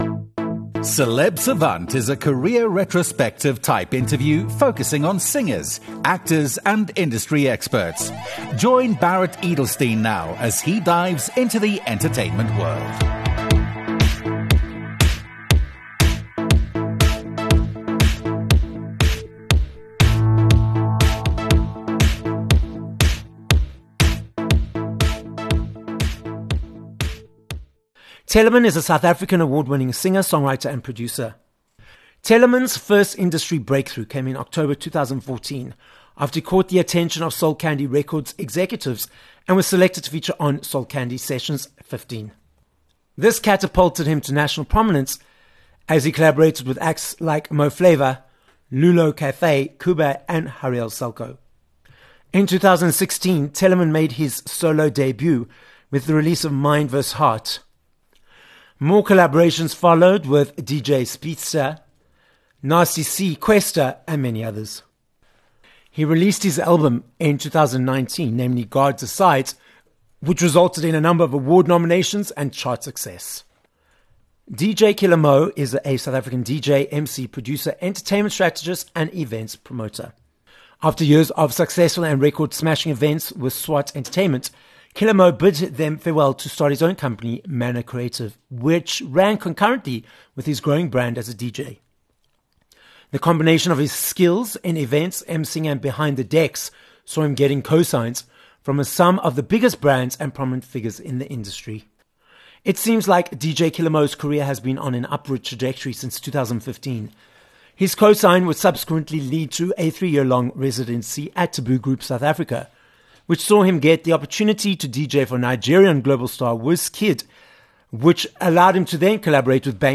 Interview
This episode of Celeb Savant was recorded live in studio at Solid Gold Podcasts in Randburg, Johannesburg, South Africa.